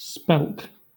Ääntäminen
Southern England
IPA : /spɛlk/